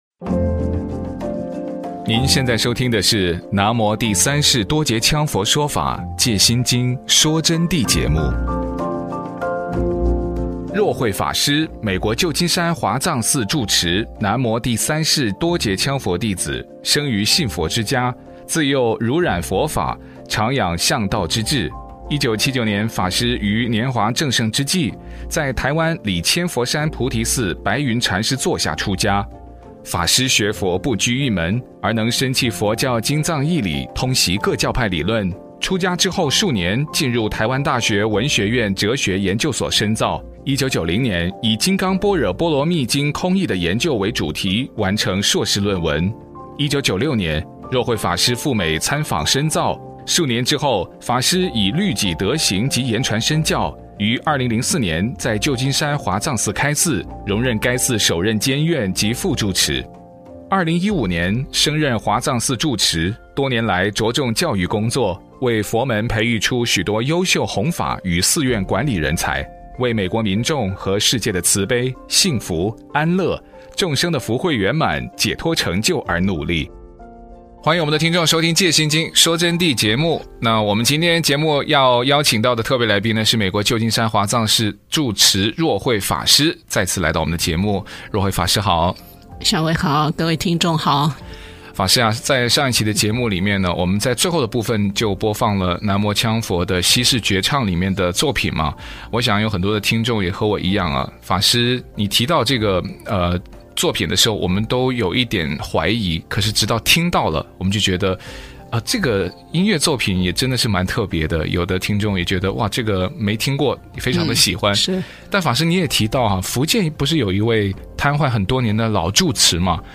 佛弟子訪談（七十五）為什麽聽南無羌佛的歌聲可以得到加持及獲得加持的事例